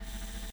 tbd-station-14/Resources/Audio/Effects/Footsteps/borgwalk3.ogg
borgwalk3.ogg